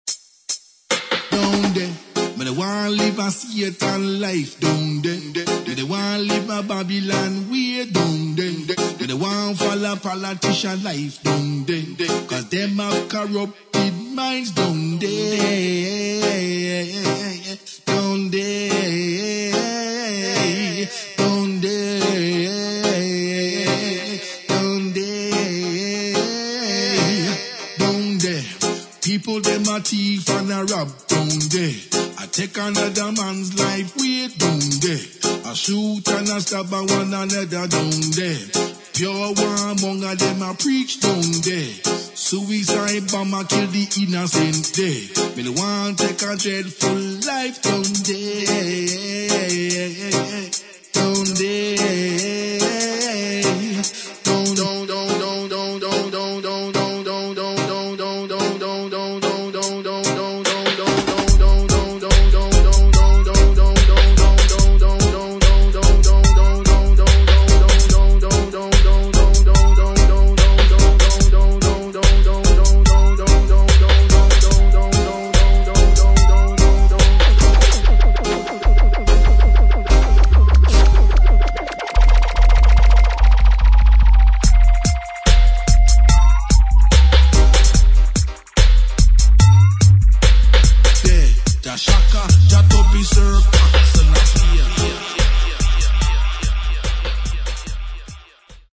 [ REGGAE / DUB / STEPPERS ]